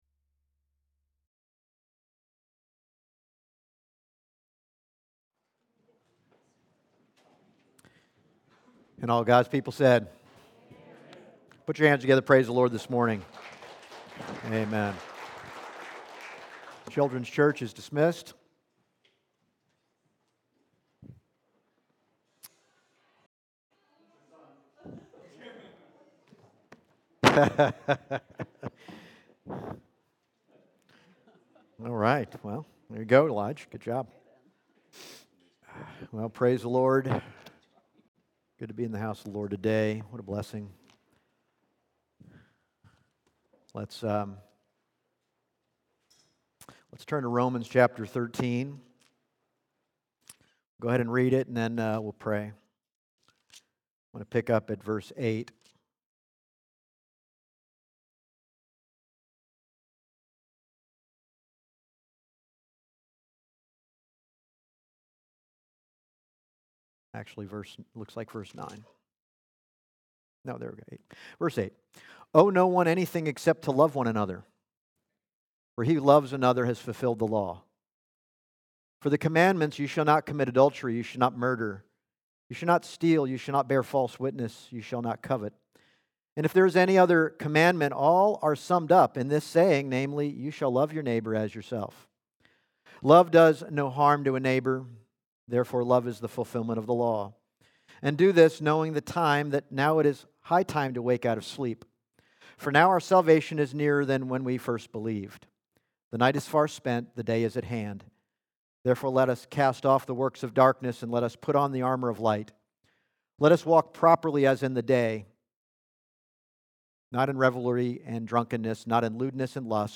Passage: Romans 13:8-14 Service Type: Sunday Morning